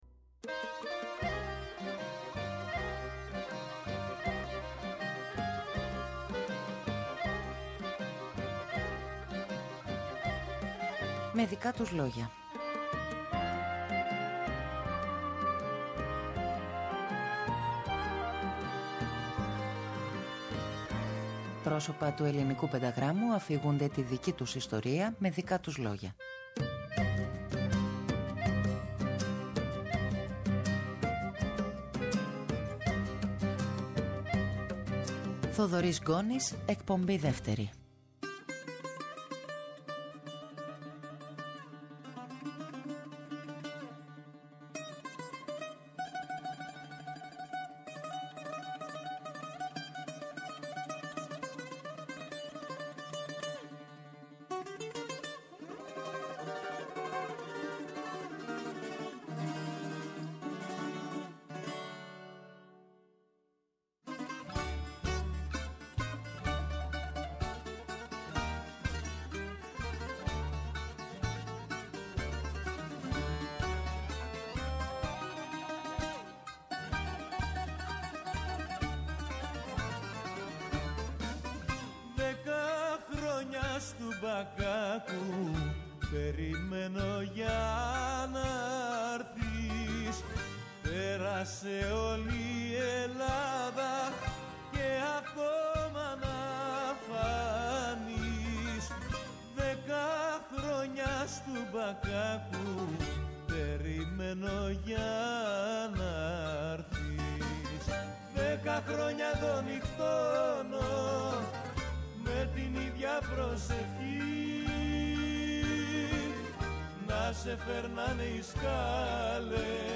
Πρόσωπα του πενταγράμμου αφηγούνται τη δική τους ιστορία…